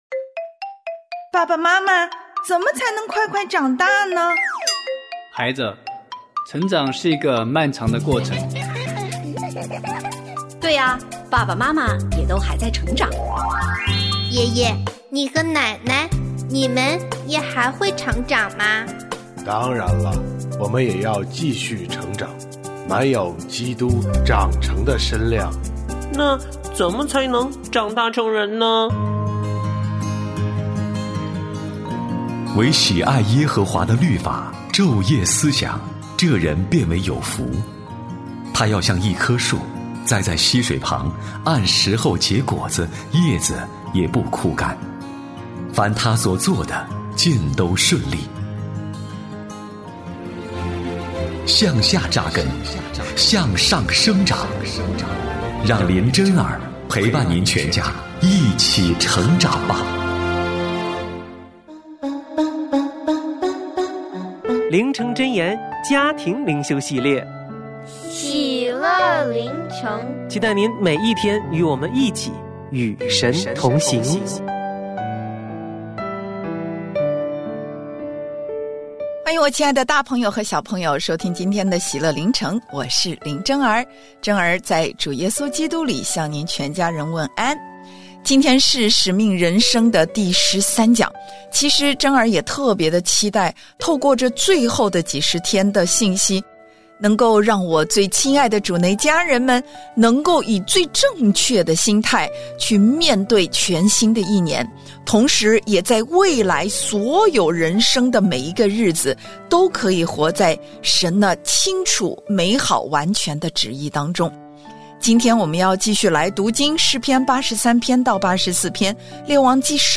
我家剧场：圣经广播剧（129）以利亚预言旱灾；以利亚经历用不尽的恩典